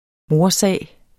Udtale [ ˈmoɐ̯- ]